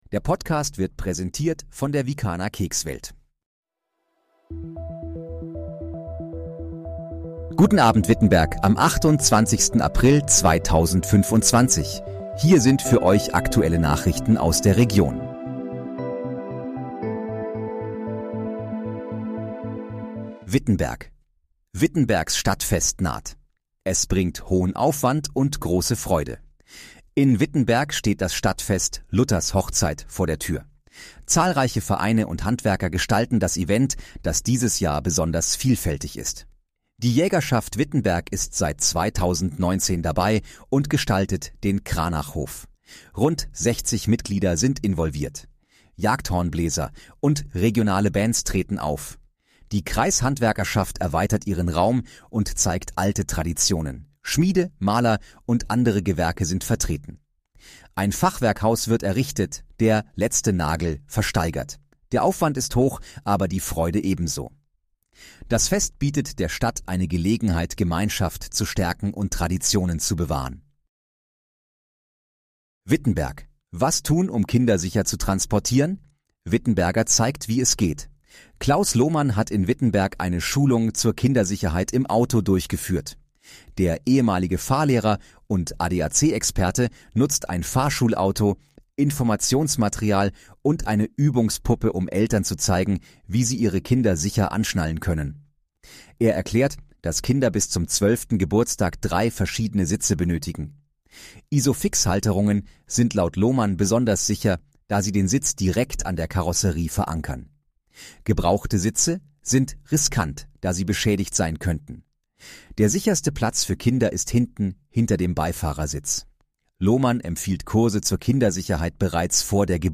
Guten Abend, Wittenberg: Aktuelle Nachrichten vom 28.04.2025, erstellt mit KI-Unterstützung
Nachrichten